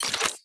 Reloading_begin0013.ogg